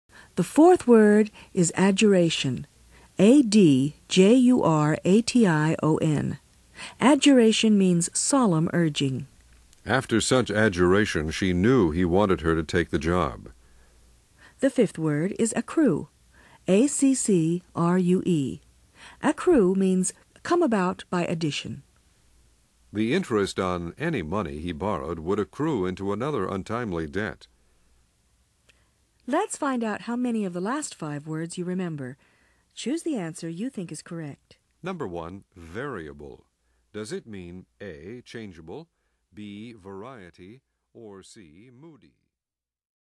Learn 1000s of new words, each pronounced, spelled, defined, and used in a sentence.